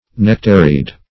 Nectaried \Nec"ta*ried\, a. Having a nectary.